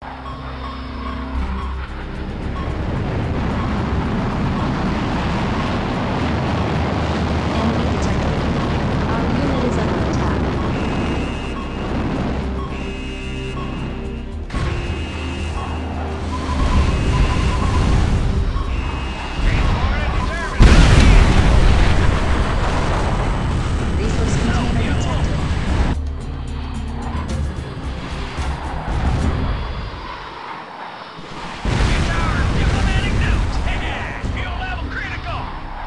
Test thử tên.lửa hạt nhân sound effects free download